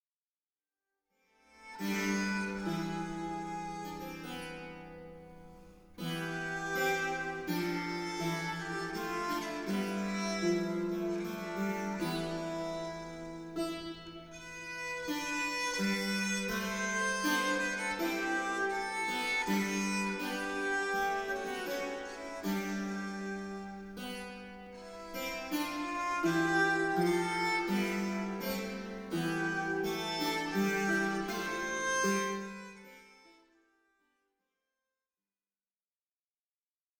Polyphonies courtoises
harpe, vièle, luth, rebec et clavicythérium